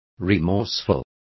Complete with pronunciation of the translation of remorseful.